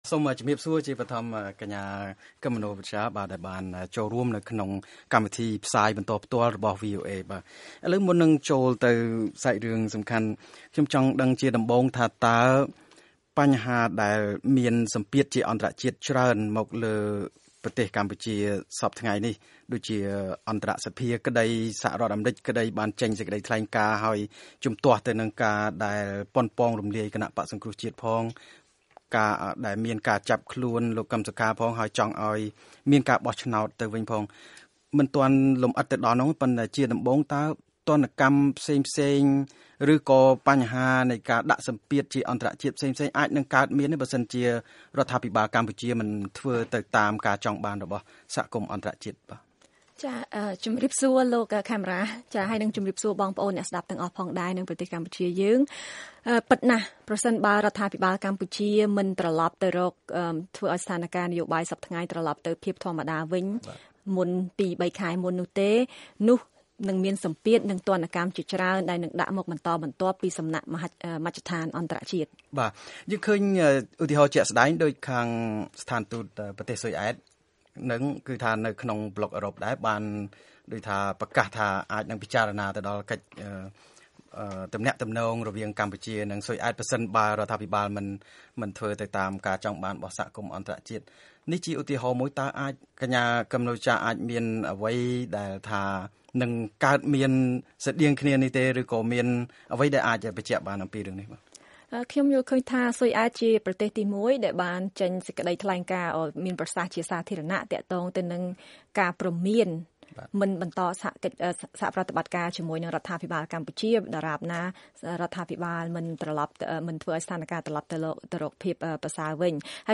បទសម្ភាសន៍ VOA៖ ស.រ.អានិងអន្តរជាតិកំពុងគិតពីច្បាប់ទណ្ឌកម្មបិទទិដ្ឋាការចំពោះមន្ត្រីកម្ពុជាខ្លះនិងទណ្ឌកម្មផ្សេងទៀត